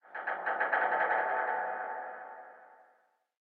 ambienturban_25.ogg